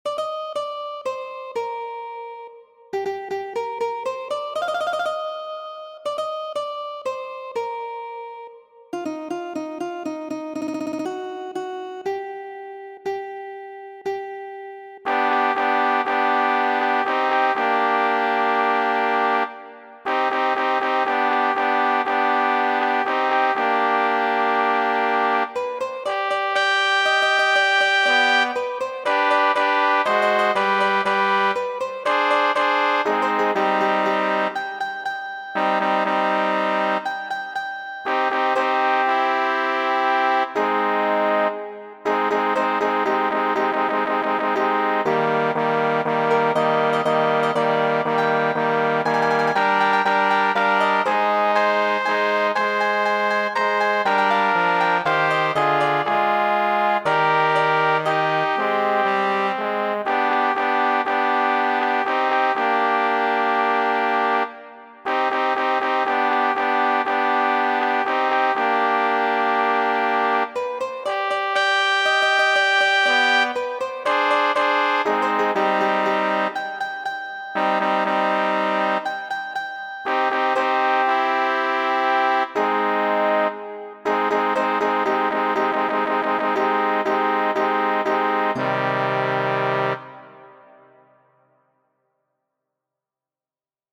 Feliĉe, ĝi ne mankas al ni, legantoj de Kajeroj el la Sudo, kiu povas legi ĝin dum ni aŭskultas varman kanton aparte kompostita por tiu ĉi evento,